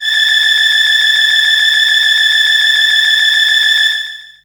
55be-syn22-a5.aif